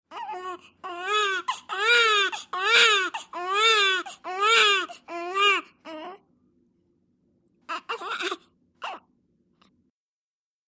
BABY NEWBORN: INT: First fragile cries.